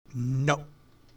Download Human Voice sound effect for free.
Human Voice